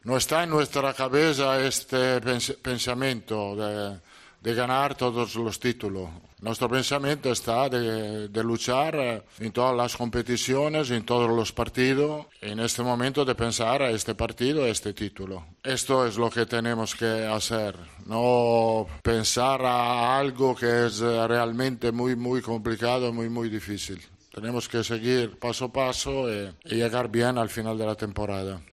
Real Madrid | Rueda de prensa